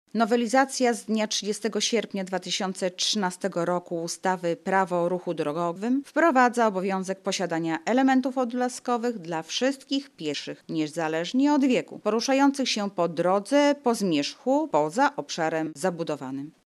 O tym, kogo obowiązuje posiadanie odblasków, mówi Anna Augustyniak, sekretarz województwa lubelskiego: